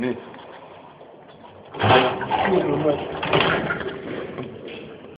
13 木门开口
描述：打开木门的声音
标签： 木材 吱吱响
声道立体声